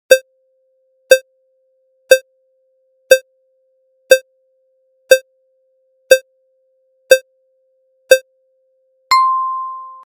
SE（カウントダウン10秒）